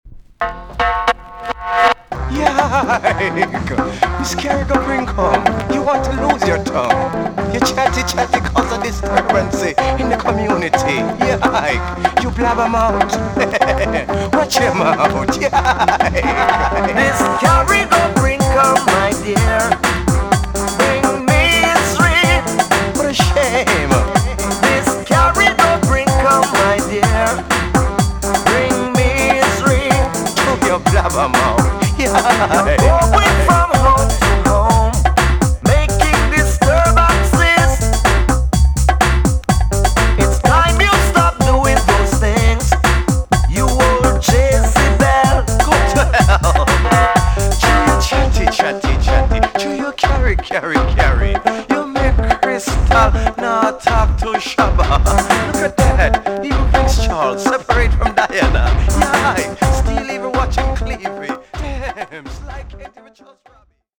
TOP >80'S 90'S DANCEHALL
EX- 音はキレイです。